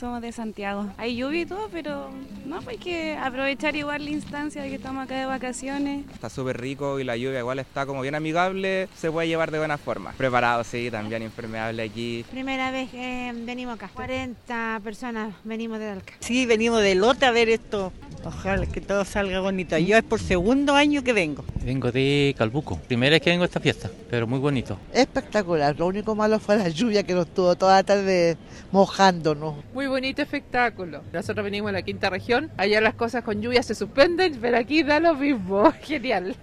En las horas previas y en medio del show, visitantes de distintas zonas del país manifestaron su alegría por estar presentes en la Noche Valdiviana, muchos de ellos preparados con sus paraguas e impermeables para la lluvia.